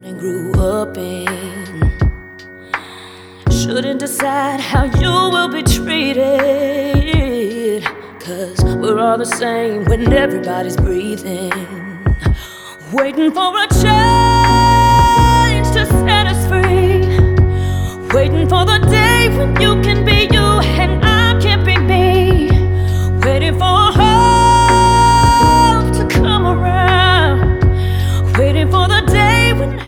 • Pop
Characterized as a slow ballad